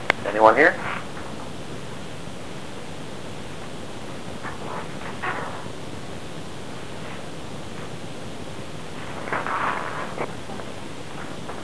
Upon reviewing the tape.....we found this remarkable EVP of decent quality.
You will hear a little period of silence and then what sounds like a spirit with an
"attitude" whisper......"Who wants to know?"
This is followed by another bit of silence....then a firm,